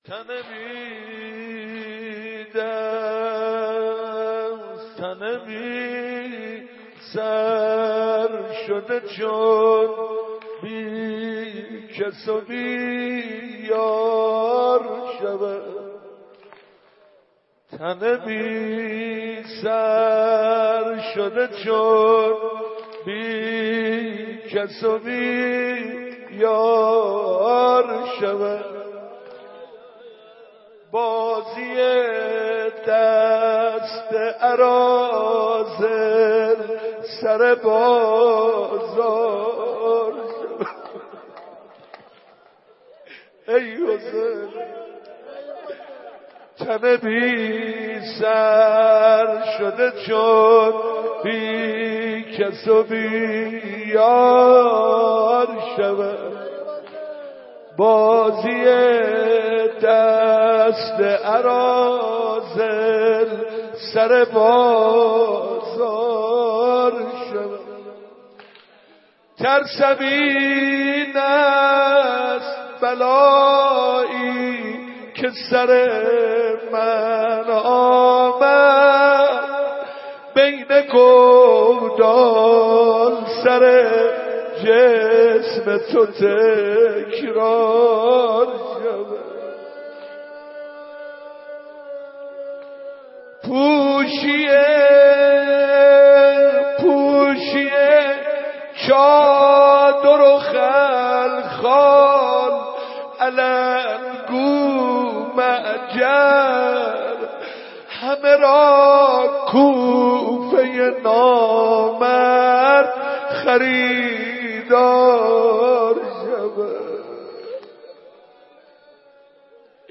شب اول محرم 96 - مسجد ارک - روضه